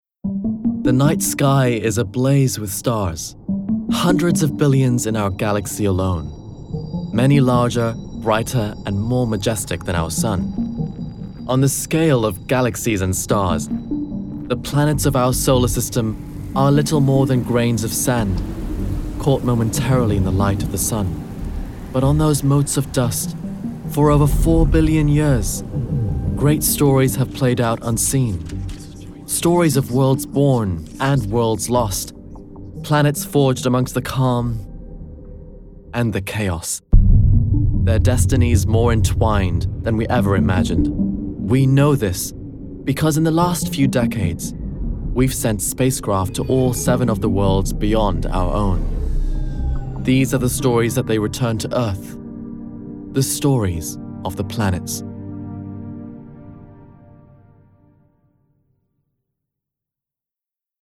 Narration Reel (English)
Narration, Bright, Informative, Versatile,
RP ('Received Pronunciation')